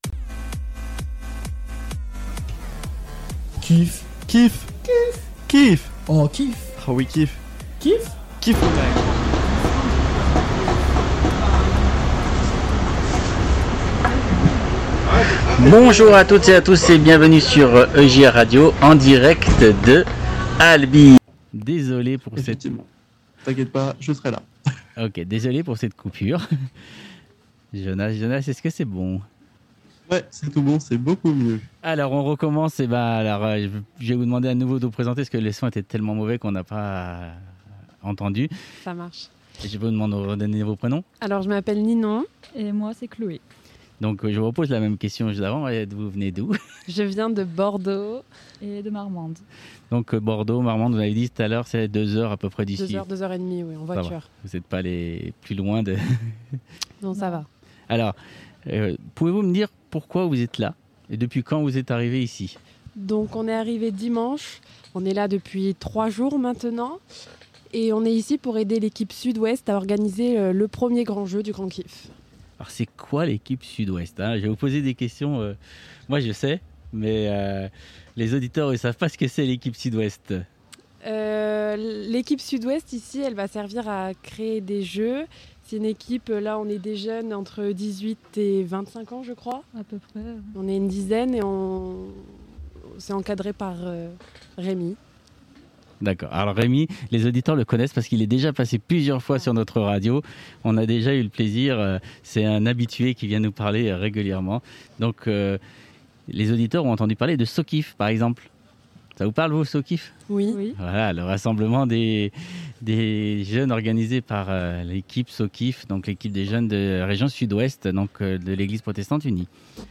Enregistré en direct de ALBI Commentaires(0) Connectez-vous pour commenter cet article Se connecter